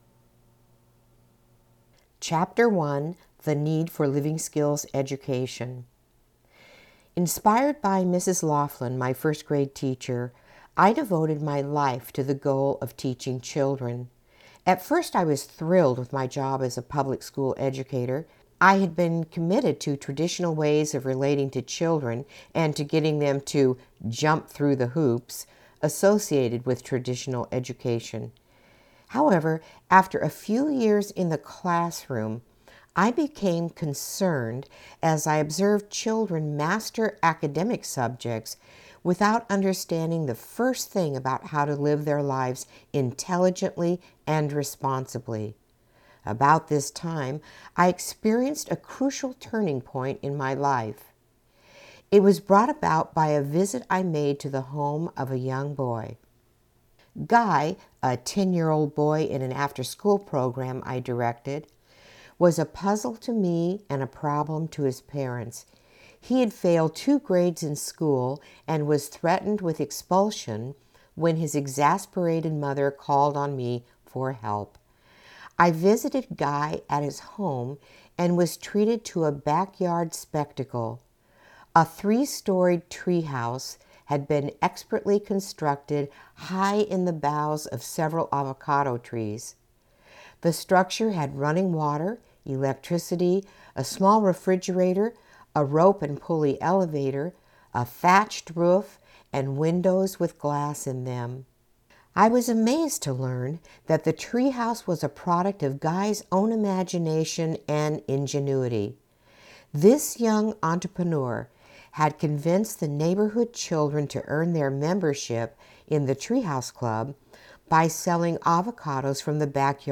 AUDIO BOOK ABOUT CHILDREN AND RESPONSIBILITY FOR PARENTS